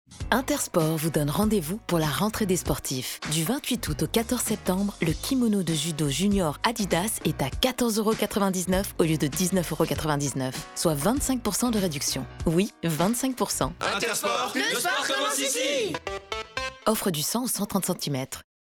Mention Légale DM - Voix persuasive